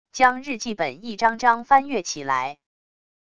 将日记本一张张翻阅起来wav音频